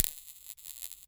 ELECZap_Bug zapper zapping, electricity, crackle,_RogueWaves_DruidMagic_37.wav